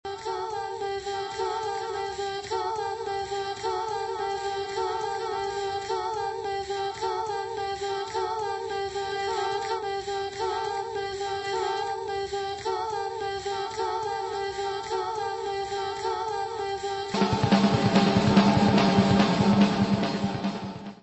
guitar, bass, samples
vocals
Music Category/Genre:  Pop / Rock